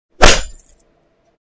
Звуки получения урона
Звук удара клинка